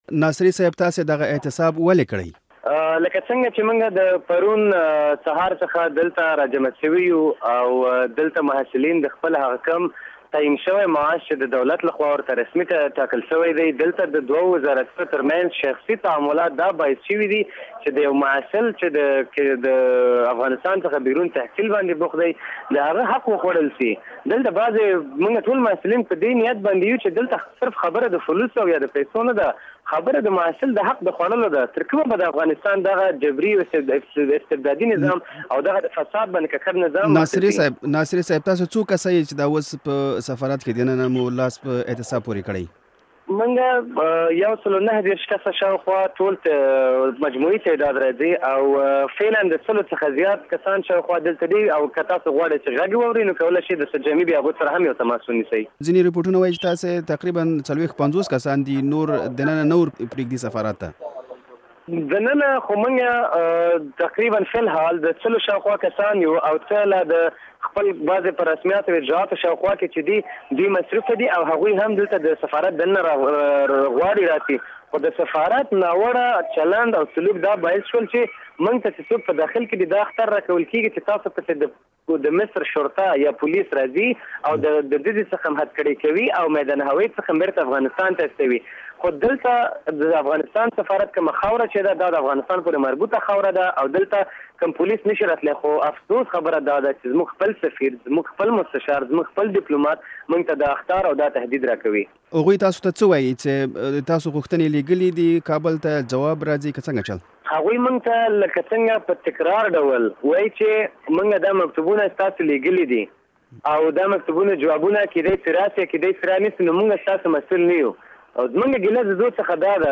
په قاهره کې له یوه اعتصاب کوونکي افغان محصل سره مرکه